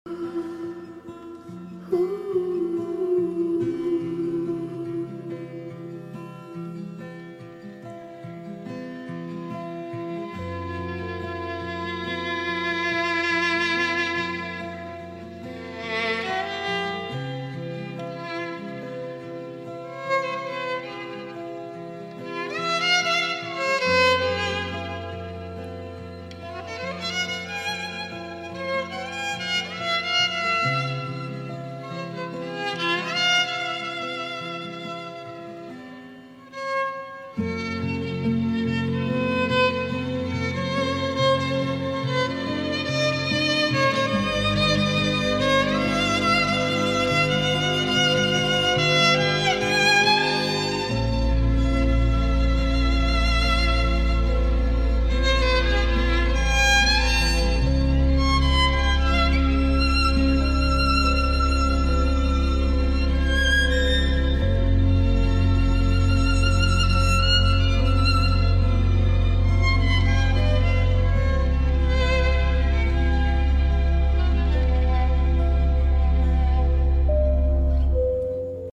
but it’s a string section solo